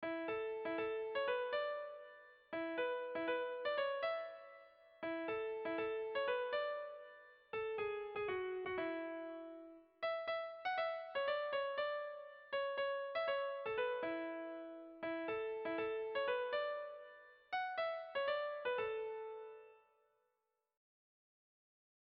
Kontakizunezkoa
Abenduko hilaren doinua dakarkigu gogora doinu honek.
Zortziko txikia (hg) / Lau puntuko txikia (ip)
A1-A2-B-A3